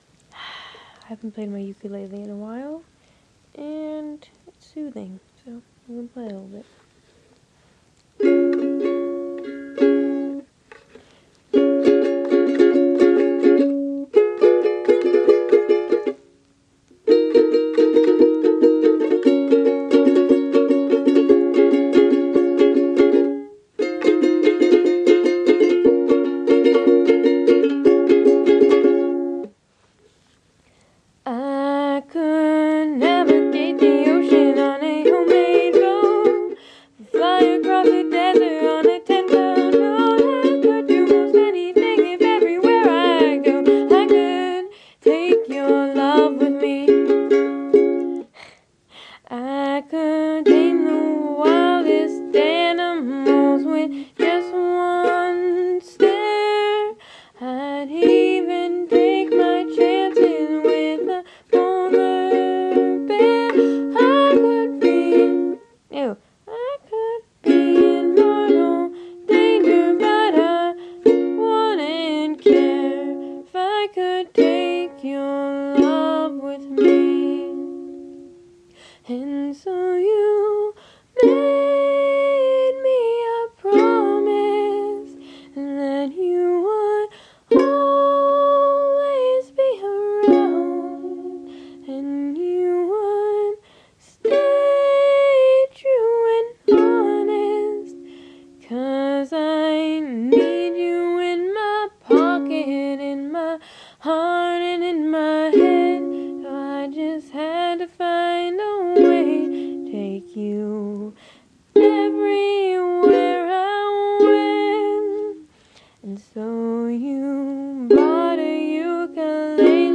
Ukulele for the first time in forever